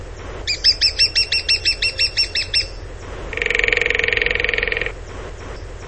Picchio rosso maggiore
Dendrocopos major
Voce
Richiamo più comune un singolo e sonoro ‘chik’ o ‘kik’; se disturbato emette un ripetuto ‘kiik-kiik-kiik’. ‘Tambureggia’ rapidamente (~ 0,6 s).
Pcchio_Rosso_Maggiore.mp3